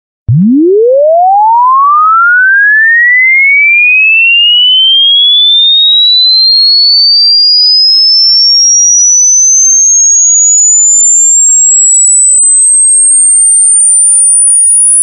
Звуки ультразвука
Ультразвуковой сигнал для СМС или звонка на телефон